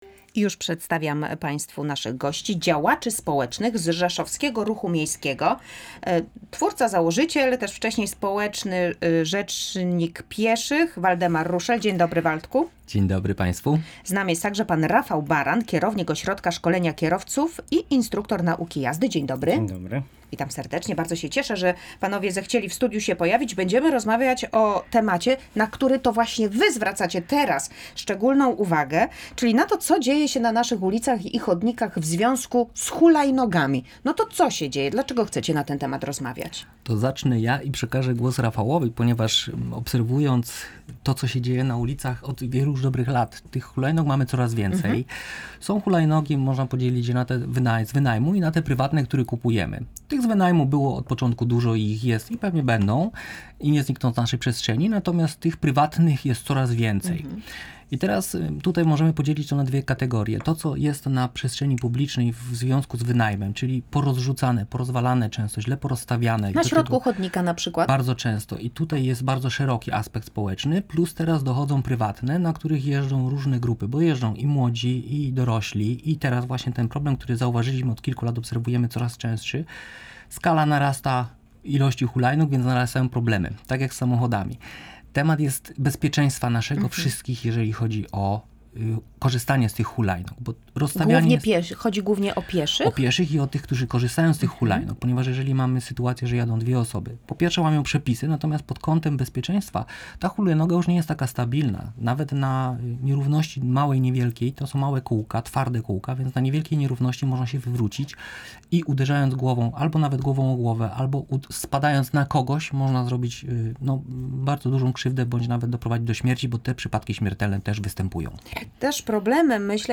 LIVE • W audycji "Tu i Teraz" rozmawialiśmy o tym, co zrobić, żeby osoby jeżdżące na hulajnogach nie były zagrożeniem dla innych uczestników ruchu